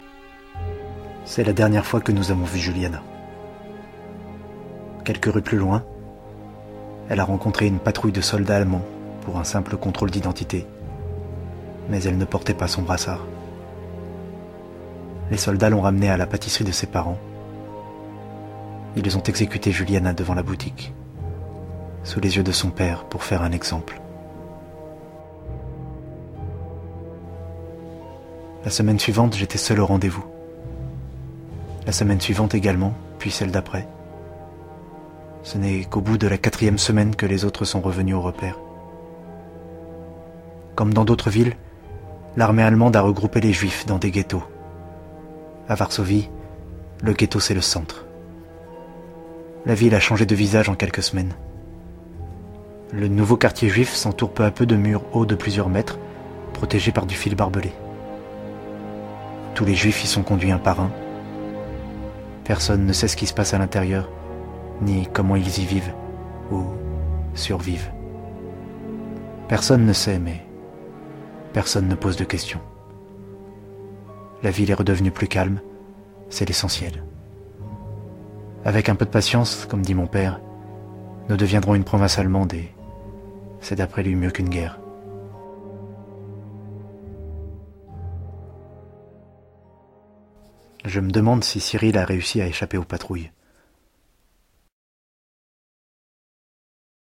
Bandes-son
extrait voix-off théâtre